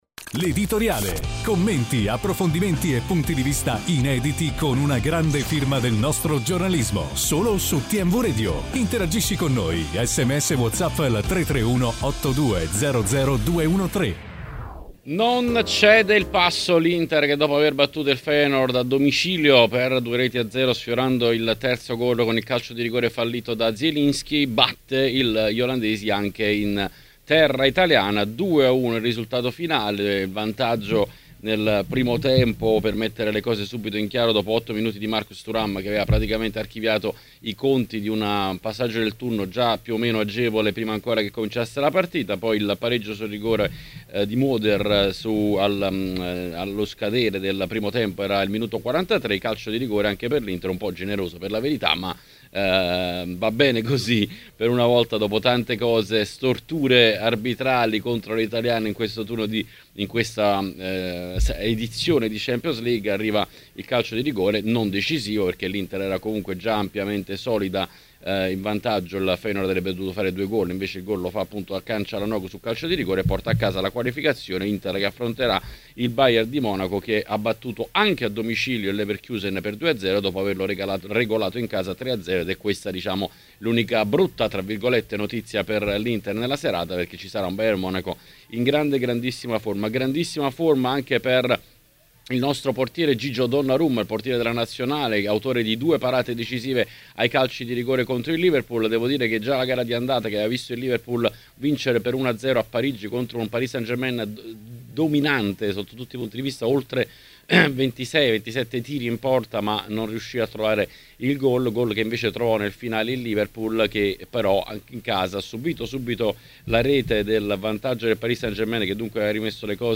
A TMW Radio, ne L'Editoriale, è stato ospite